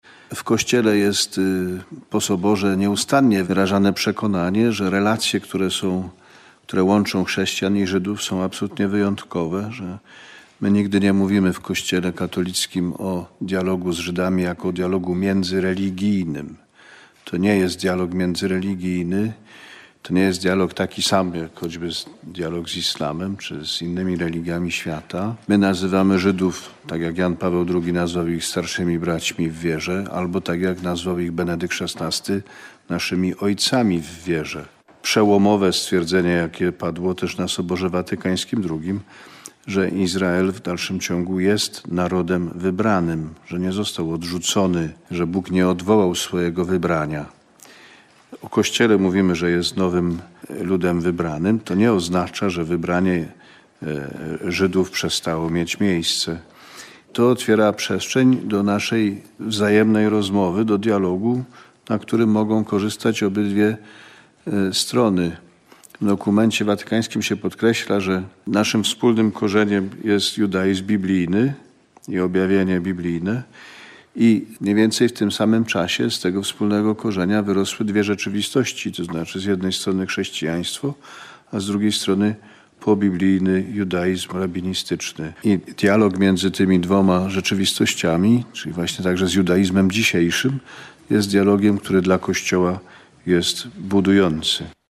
-Celem dialogu jest wzajemne poznanie. Drugim celem jest możliwe współdziałanie, zwłaszcza na rzecz pokoju – powiedział kard. Grzegorz Ryś, przewodniczący Komitetu Konferencji Episkopatu Polski ds. Dialogu z Judaizmem, podczas konferencji prasowej przed XXVII Dniem Judaizmu w Kościele katolickim w Polsce, w wigilię Tygodnia Modlitw o Jedność Chrześcijan.